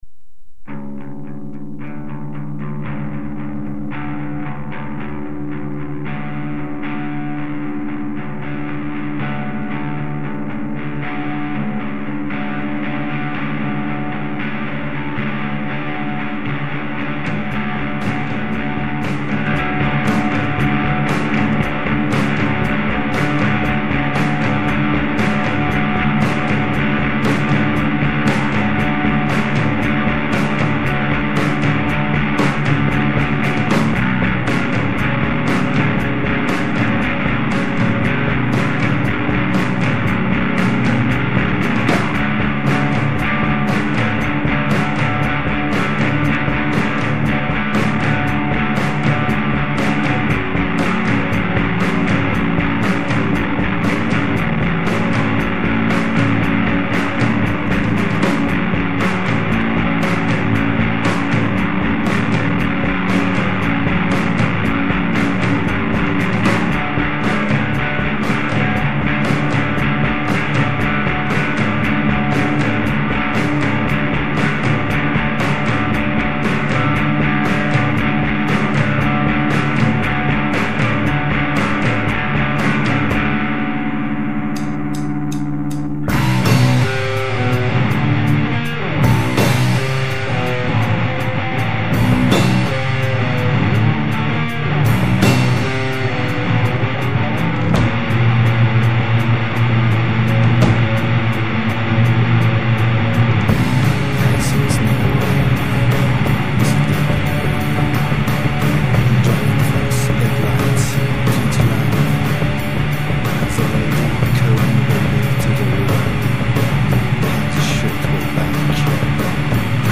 Post punk